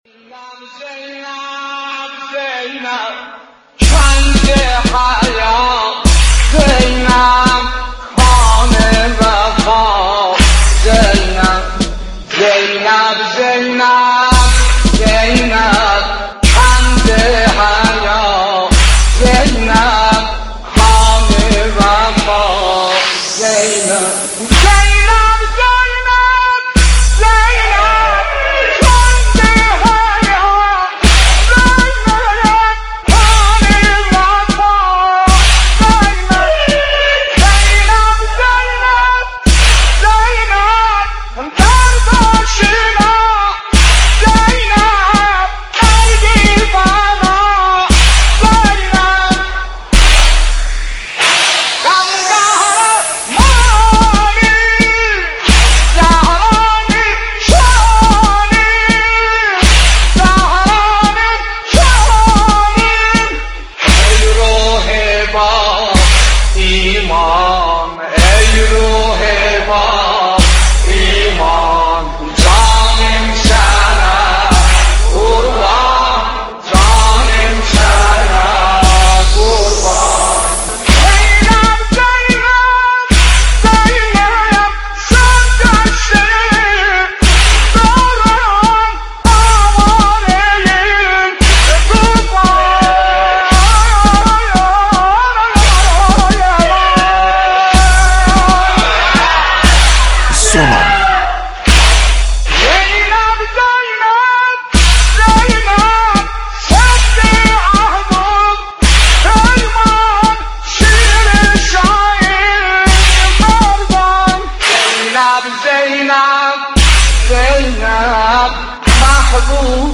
سلیم موذن‌زاده اردبیلی با صوت روحانی خود، حنجره‌اش را تقدیم اهل بیت(ع) كرد و امروز بعد از گذشت 6 سال از درگذشتش كه همزمان با آخرین روزهای ماه صفر بود، هنوز هم صدای «زینب زینبش» جزو سوزناك‌ترین نواهای عاشورایی است.
رادیو آوا- سلیم موذن‌زاده اردبیلی همان نوحه‌خوانی است كه نوحه ماندگار او در وصف حضرت زینب(س) شهرت فراوانی دارد و امروز با گذشت 6 سال از درگذشت او همچنان جایش در مراسم سوگواری امام حسین (ع) خالی است.